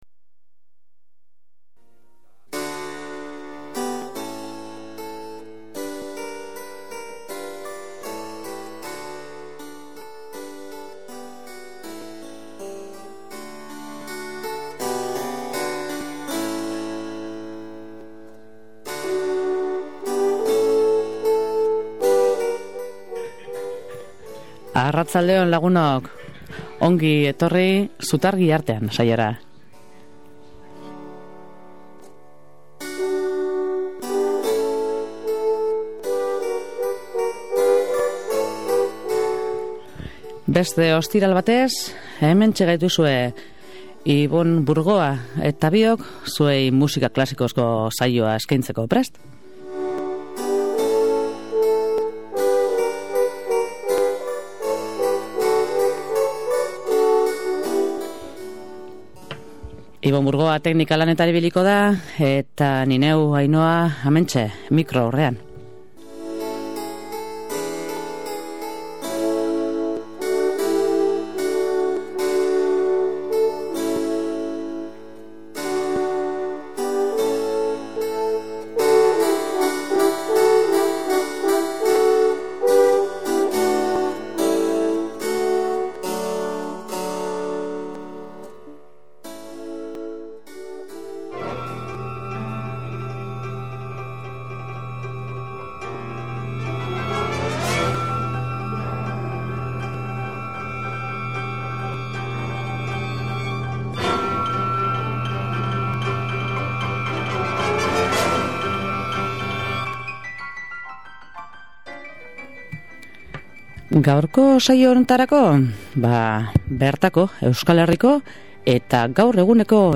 Batetik piano eta orkestrarako bi lan
piano eta orkestrarako kontzertu bat